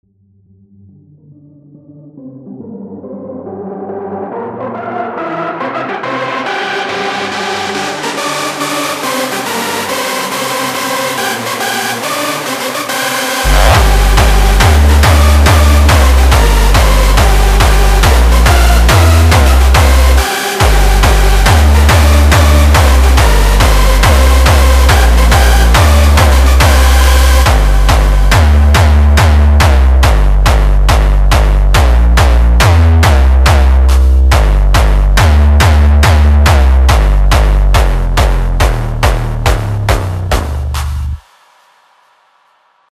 Вложения jumpstyle_bass_drumm.mp3 jumpstyle_bass_drumm.mp3 670,7 KB · Просмотры: 406